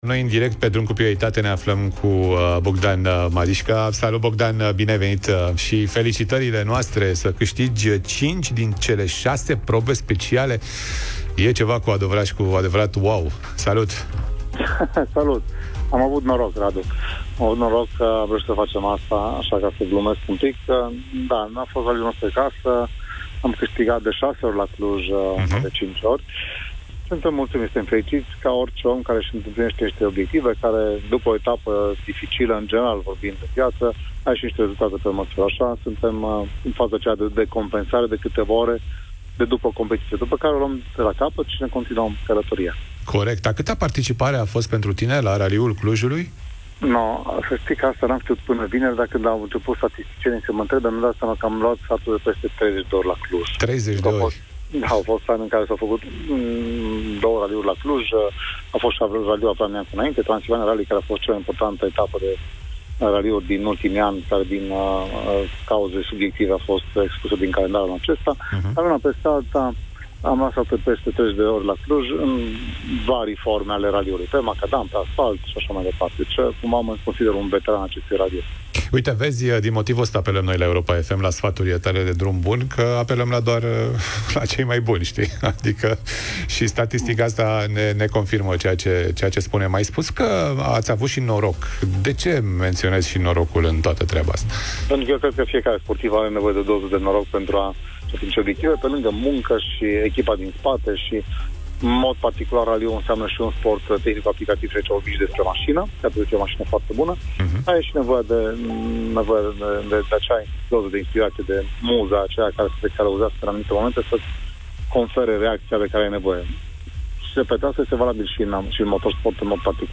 într-o intervenție în direct